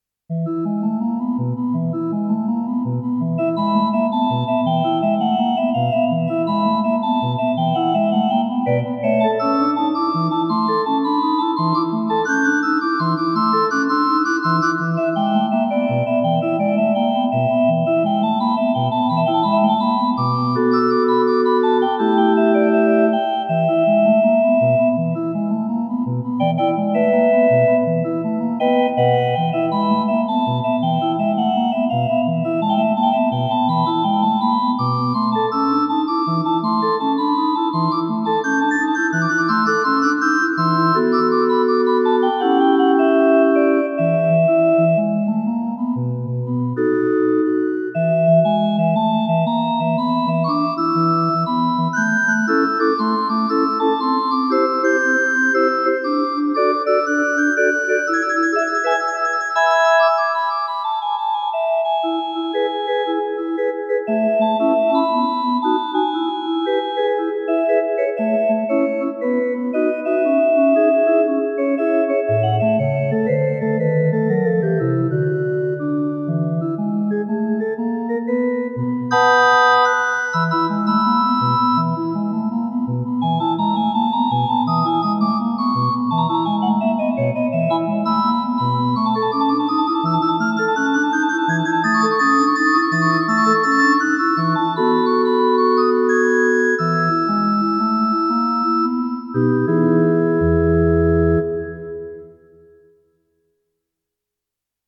WAS DER AFFE AM MONTAG GESPIELT HAT (ORGEL)